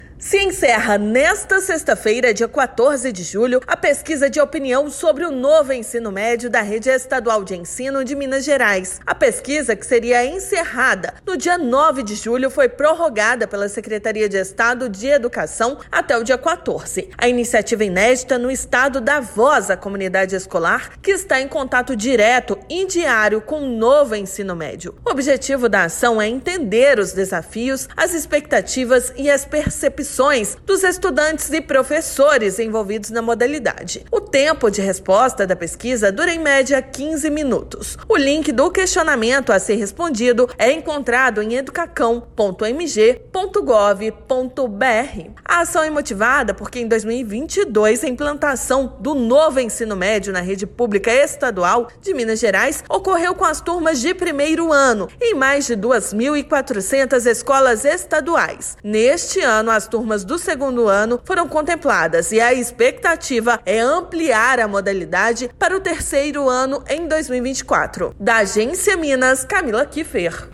Na consulta pública, estudantes, professores e gestores escolares da rede estadual são convocados a compartilhar as percepções e expectativas sobre o Novo Ensino Médio (NEM). Ouça matéria de rádio.